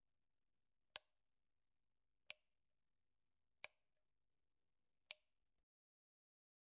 钟声 SFX - 声音 - 淘声网 - 免费音效素材资源|视频游戏配乐下载
挂钟的滴答声是无缝的，可以很容易地乘以你自己的喜好。